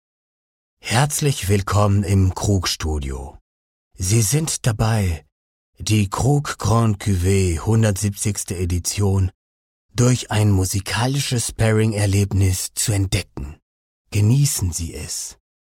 publicité en allemand